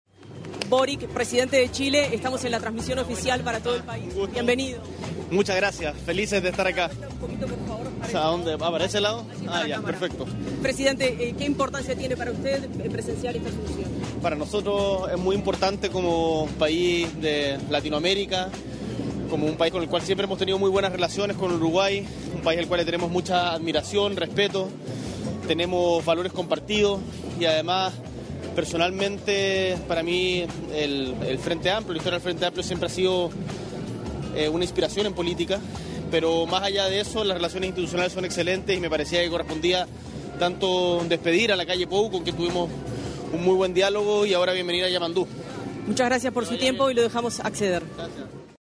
Palabras del presidente de Chile, Gabriel Boric
Palabras del presidente de Chile, Gabriel Boric 01/03/2025 Compartir Facebook X Copiar enlace WhatsApp LinkedIn Este sábado 1.° de marzo, en oportunidad del traspaso de mando presidencial en Uruguay, se expresó el mandatario de la República de Chile, Gabriel Boric.